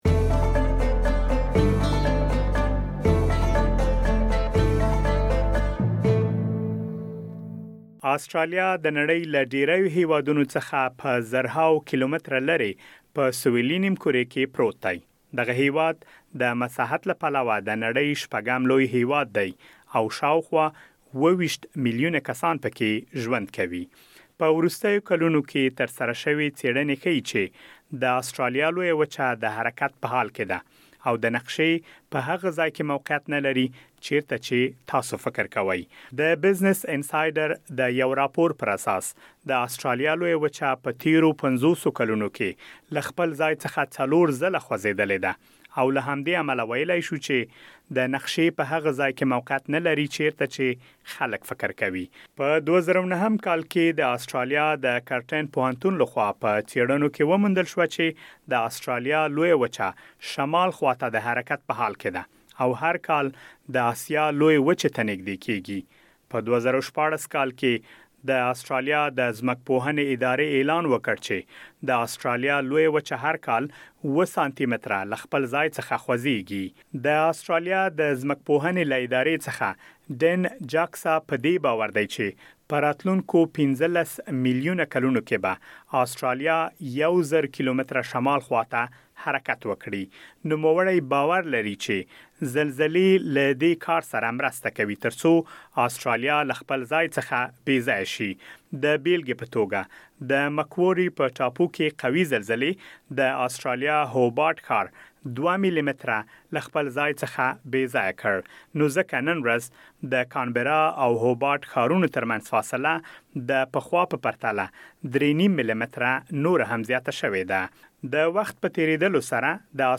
په ورستیو کلونو کې ترسره شوې څېړنې ښيي چې آسټرالیا د نقشې په هغه ځای کې موقعیت نلري چېرته چې تاسو فکر کوئ او شمال خوا ته د حرکت په حال کې ده. د آسټرالیا د جغرافیې په اړه مهم معلومات په رپوټ کې اورېدلی شئ.